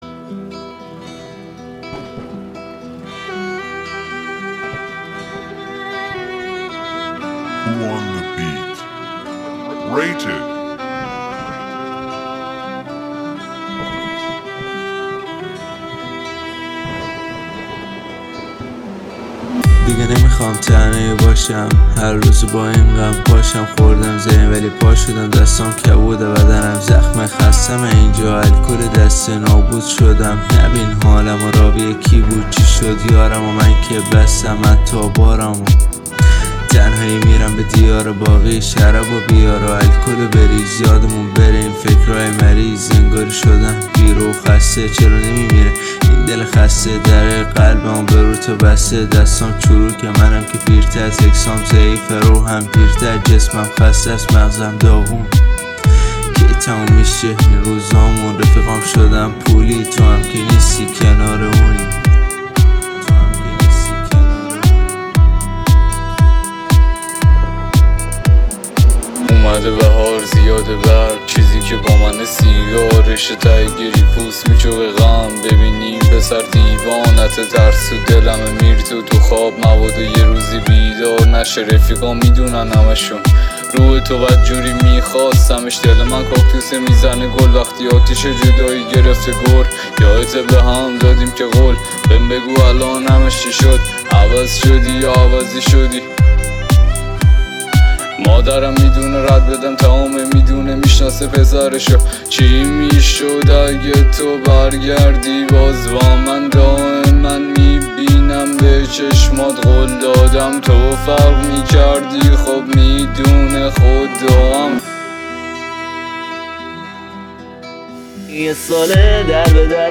دپ ترک دپ رپ ریمیکس